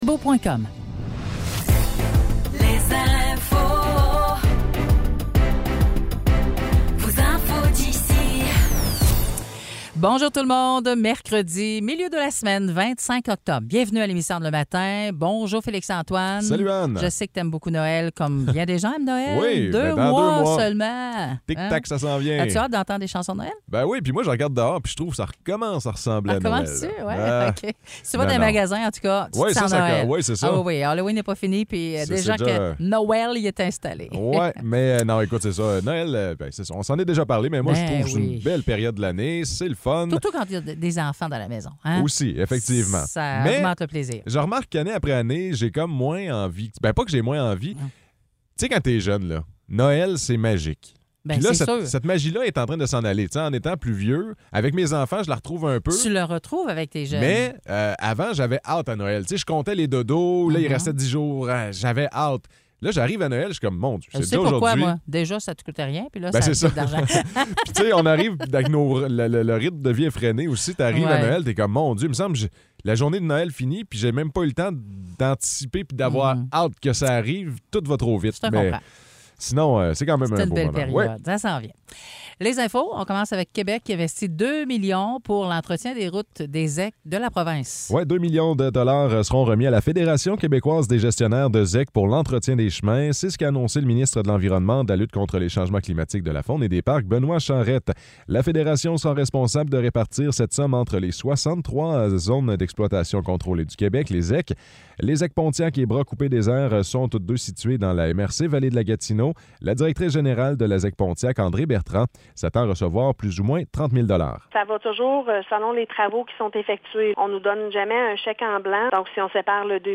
Nouvelles locales - 25 octobre 2023 - 9 h